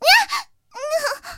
T-127小破语音1.OGG